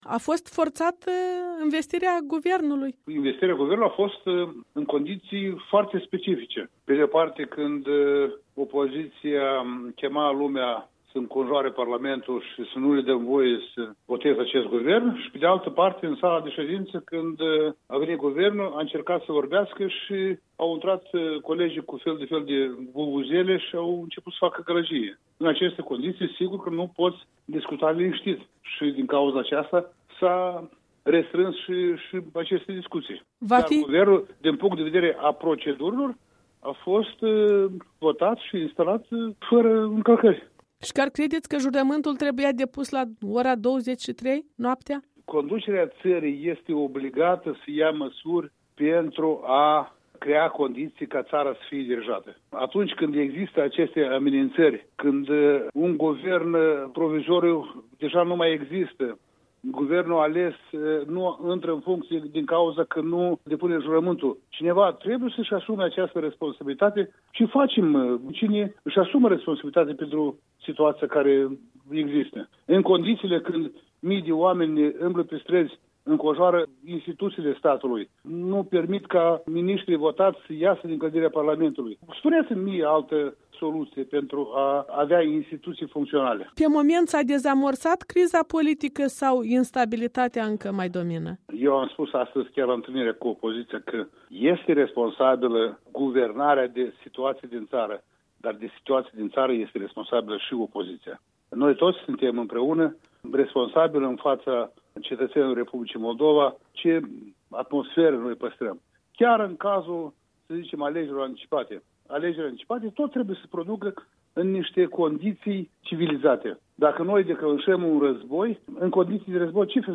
Interviu cu Dumitru Diacov